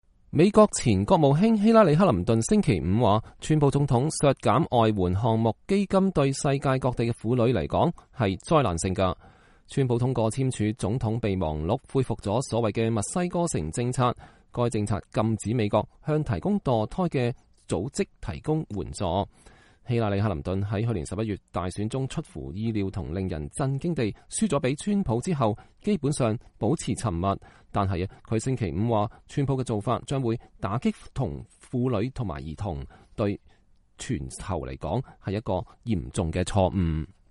希拉里克林頓在喬治城大學婦女、和平與安全研究所舉行的希拉里克林頓獎年度頒獎儀式上講話（2017年3月31日）